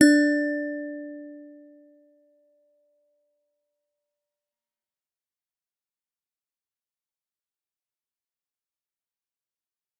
G_Musicbox-D4-f.wav